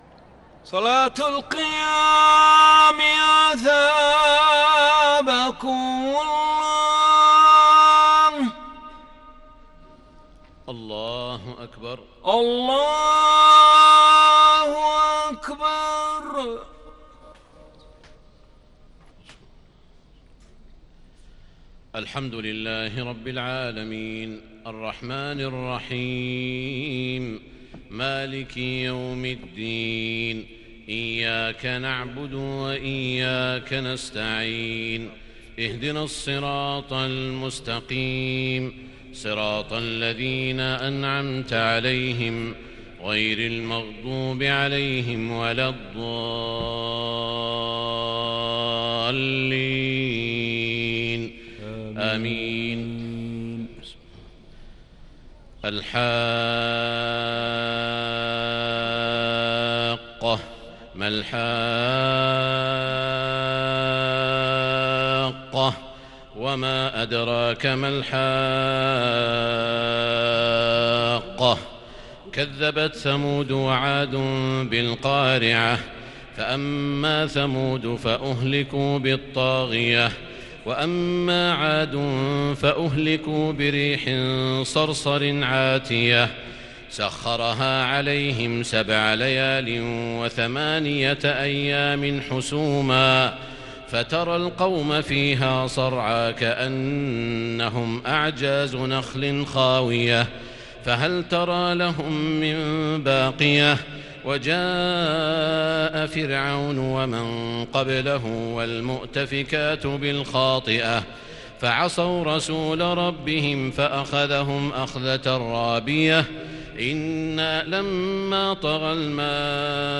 تراويح ليلة 29 رمضان 1441هـ من سورة الحاقة إلى سورة الجن | taraweeh 29 st niqht Ramadan 1441H from Surah al-Haqqah to AlJinn > تراويح الحرم المكي عام 1441 🕋 > التراويح - تلاوات الحرمين